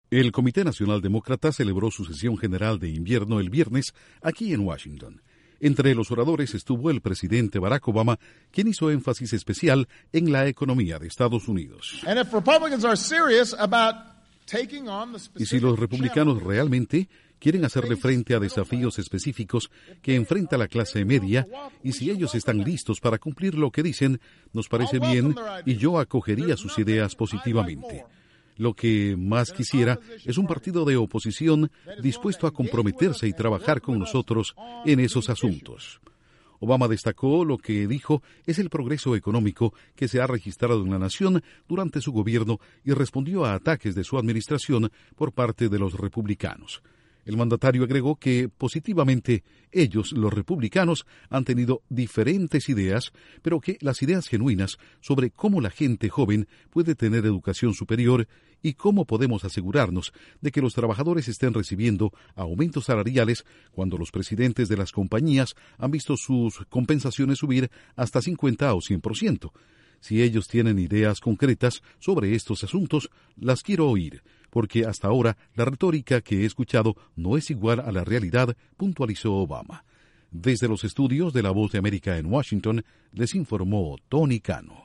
Obama hizo fuertes críticas a los republicanos durante la reunión de invierno del Comité Nacional Demócrata. Informa desde los estudios de la Voz de América en Washington